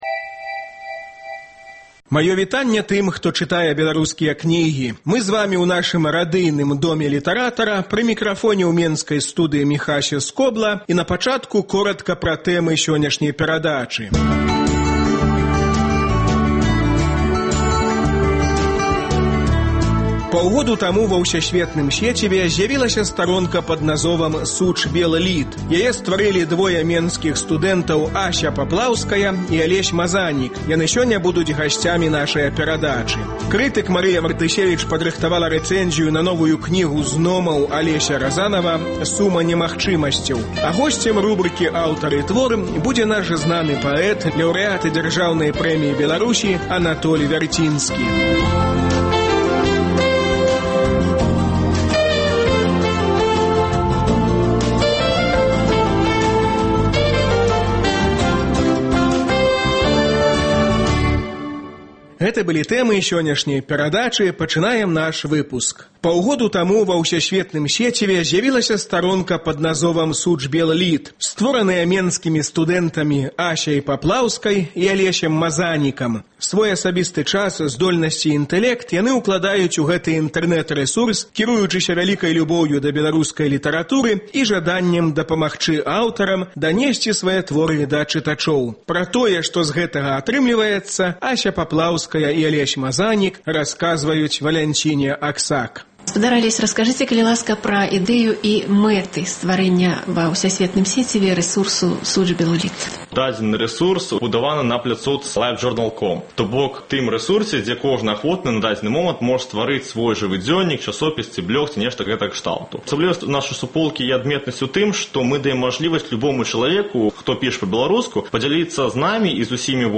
У рубрыцы “Аўтар і твор” свае новыя вершы чытае Анатоль Вярцінскі.